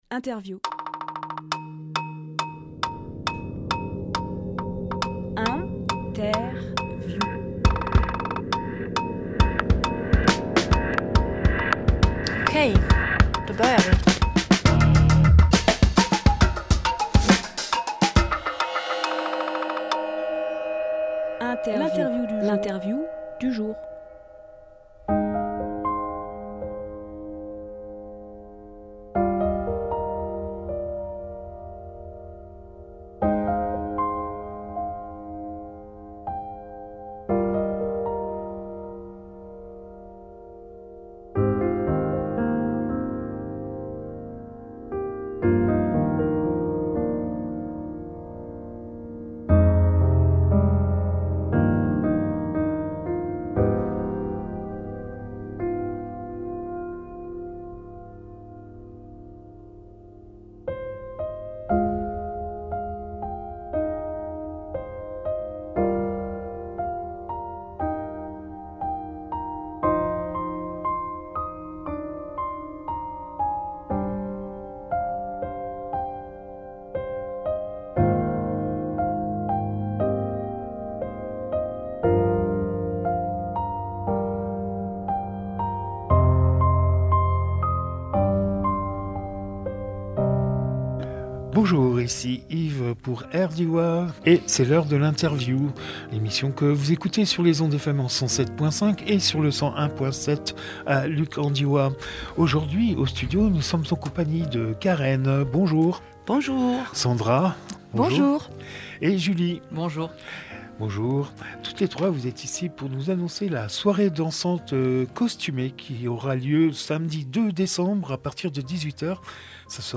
Emission - Interview Soirée dansante costumée au Pestel Café Publié le 28 novembre 2023 Partager sur…
24.11.23 Lieu : Studio RDWA Durée